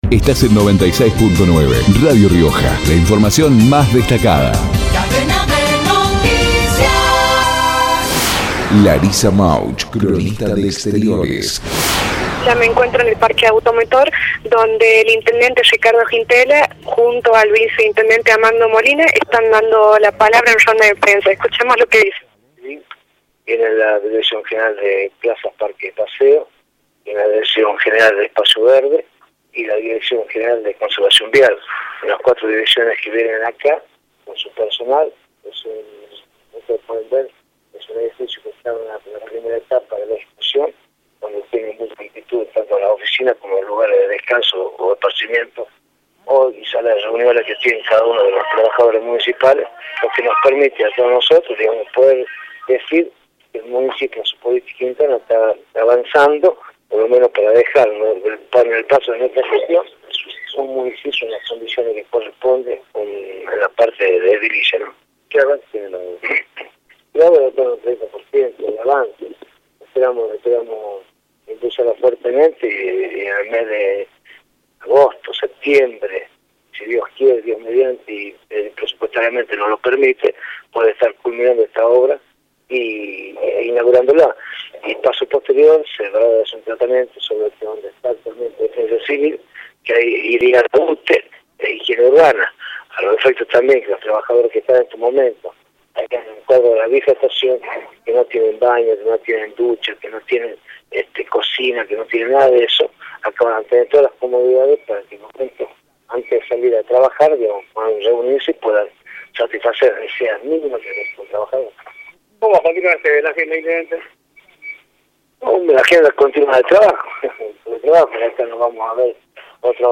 Ricardo Quintela, intendente, por Radio Rioja